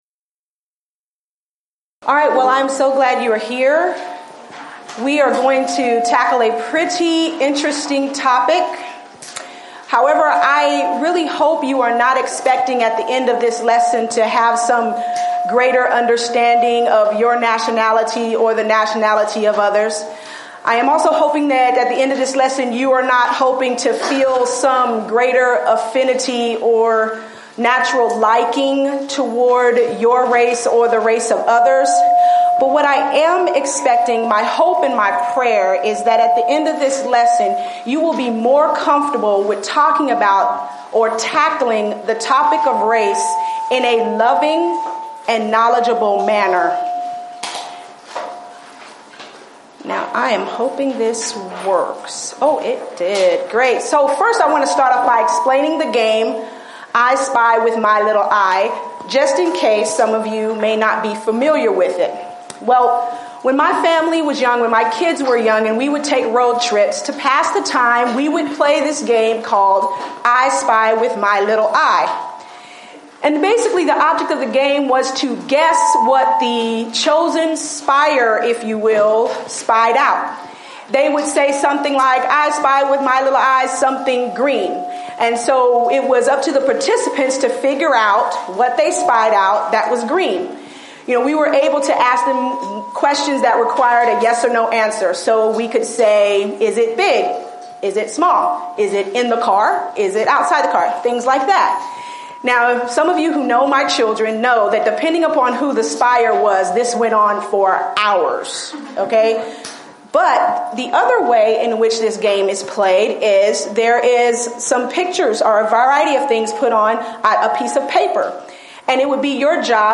Event: 2015 Discipleship University
lecture